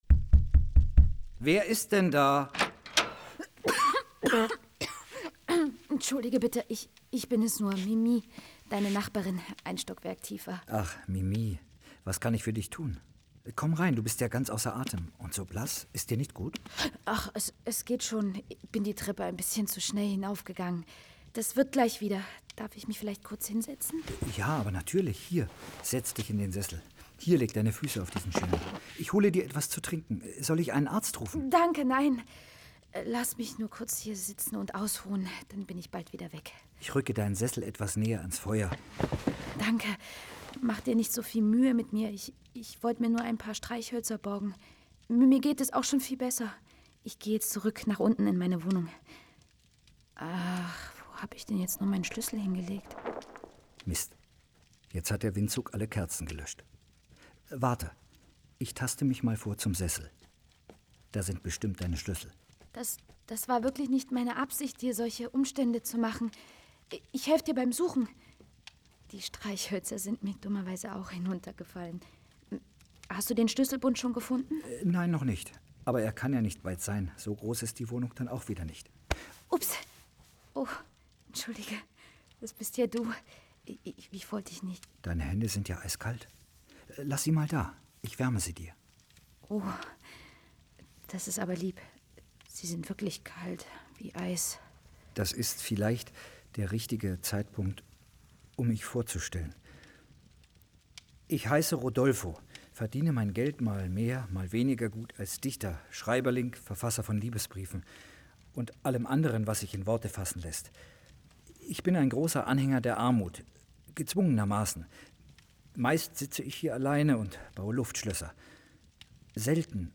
La Bohème Oper erzählt als Hörspiel mit Musik Giacomo Puccini